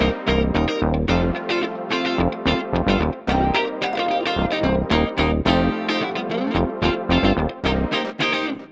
30 Backing PT4.wav